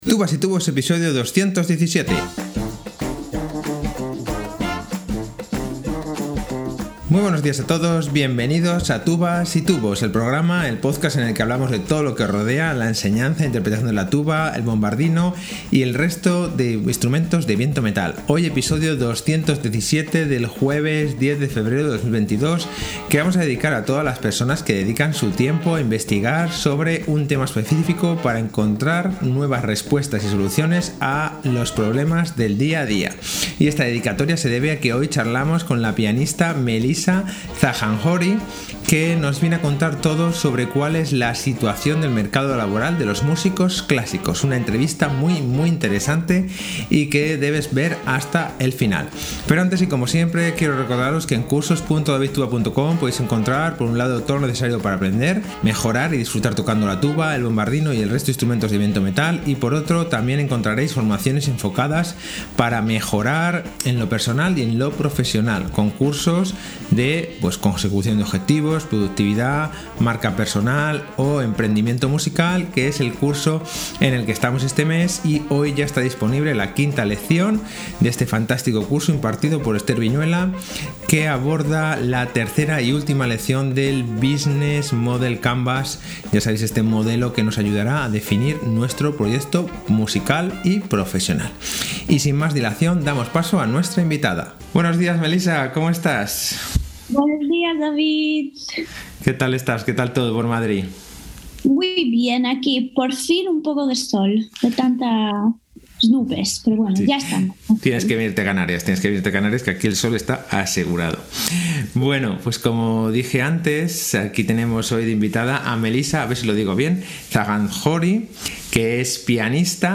una entrevista muy muy interesante y que debes ver hasta el final